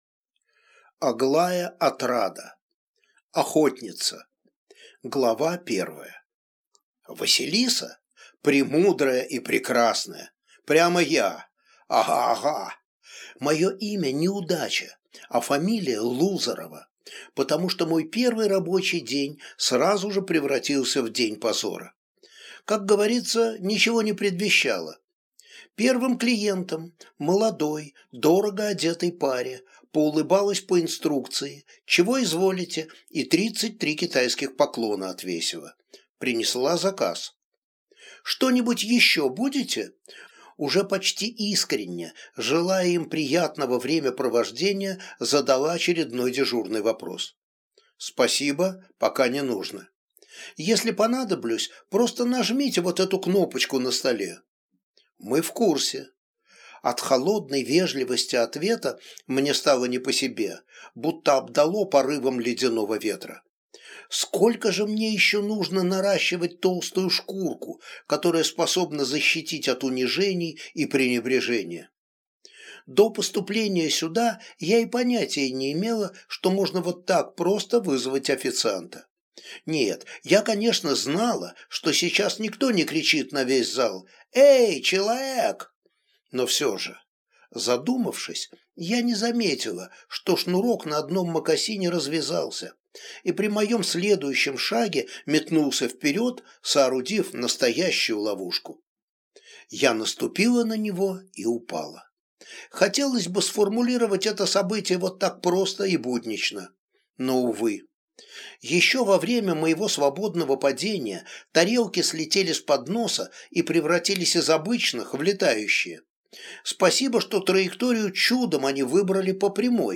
Аудиокнига Охотница | Библиотека аудиокниг
Прослушать и бесплатно скачать фрагмент аудиокниги